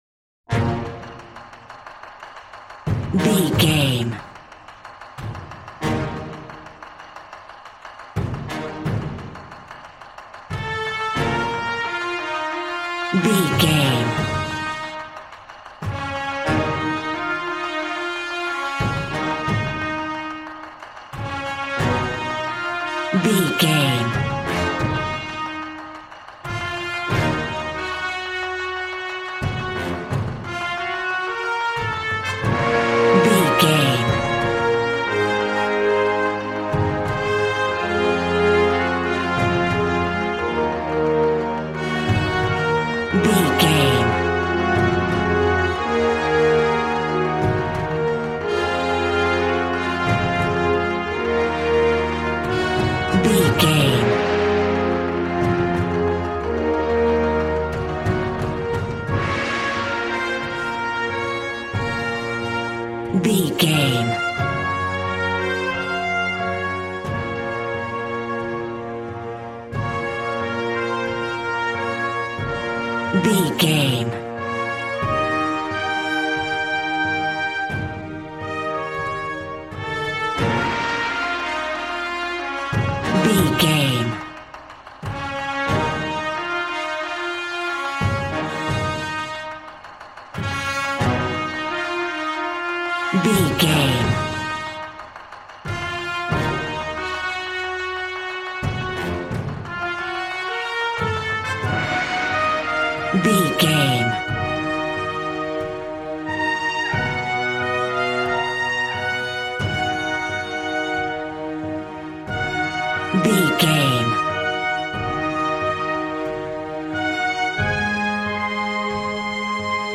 Action and Fantasy music for an epic dramatic world!
Aeolian/Minor
B♭
hard
groovy
drums
bass guitar
electric guitar